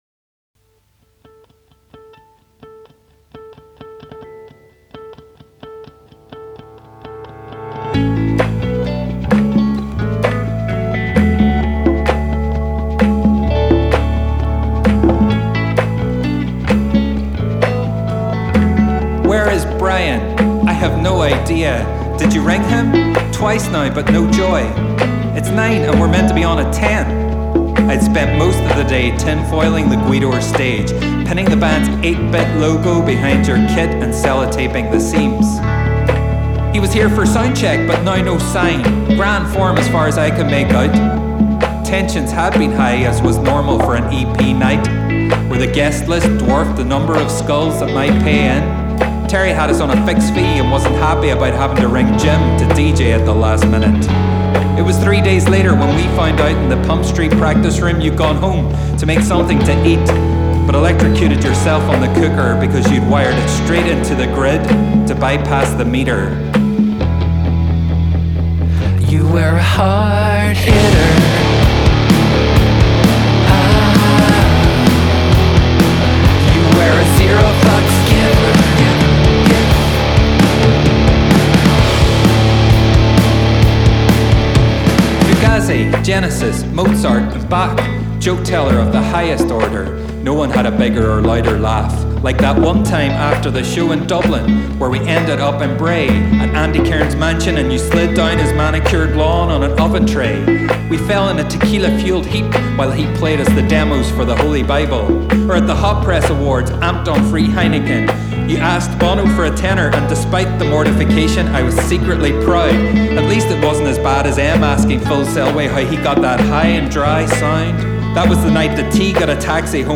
a major
dadgbe